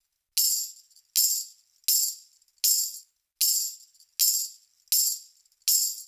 Index of /musicradar/sampled-funk-soul-samples/79bpm/Beats
SSF_TambProc2_79-01.wav